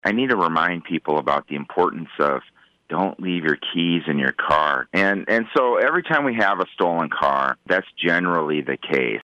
Police Chief Mike Tupper mentions that there have been a few stolen cars recently in Marshalltown.  He joins KFJB earlier this week to discuss the uptick.